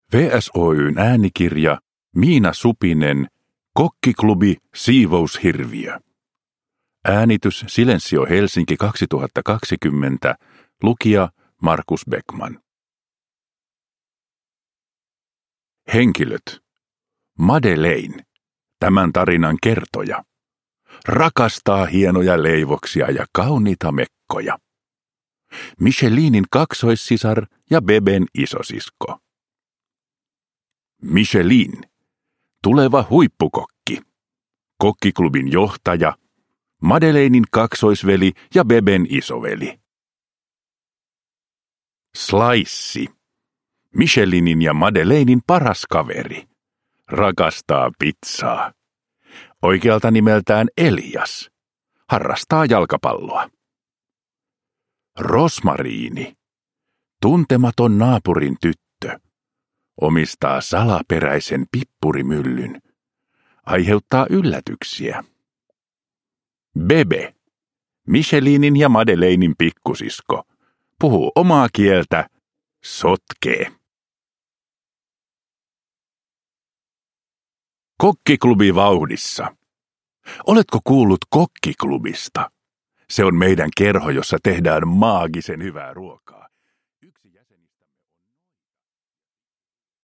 Kokkiklubi: Siivoushirviö – Ljudbok – Laddas ner